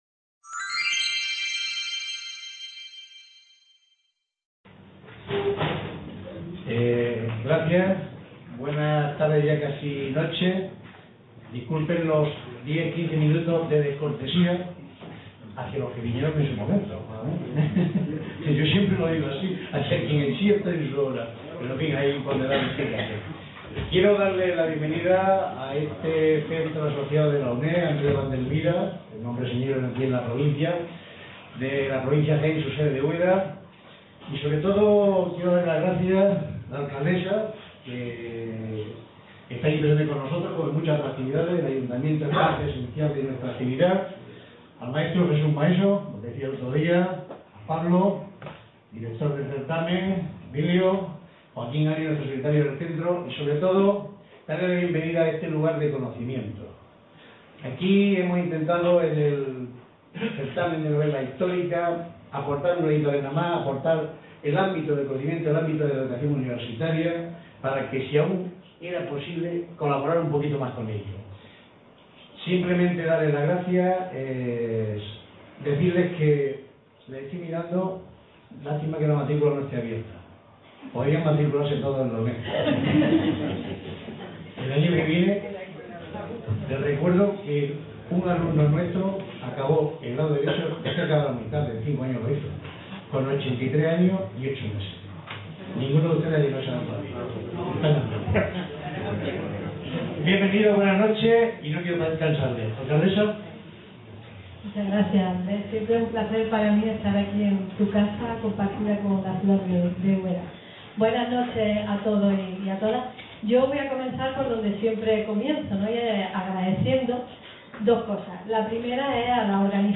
Certamen Internacional de Novela Histórica Ciudad de Úbeda